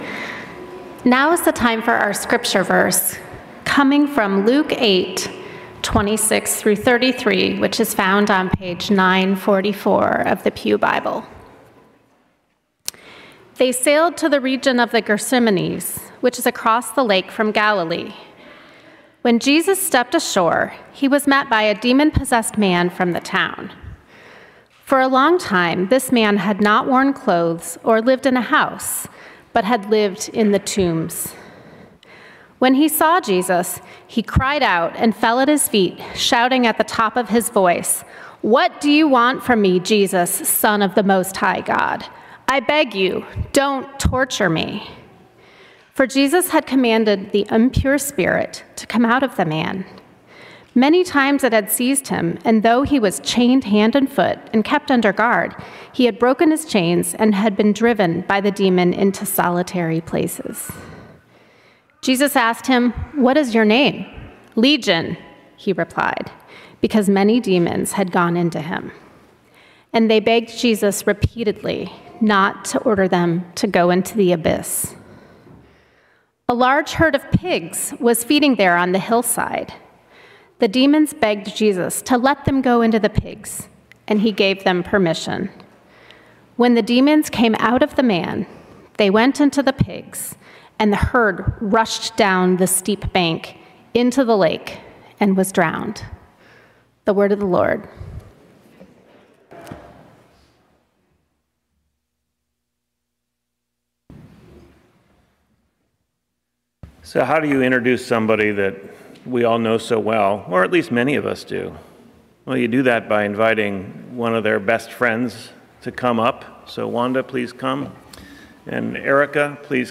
Sermons | Washington Community Fellowship